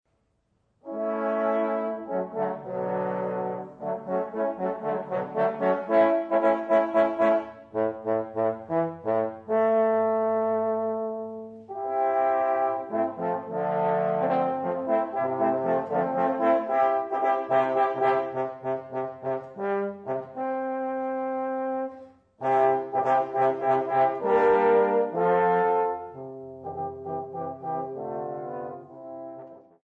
I. Fanfare: a stately and majestic overture
Quartetto di Tromboni
Trombone quartet